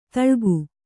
♪ taḷgu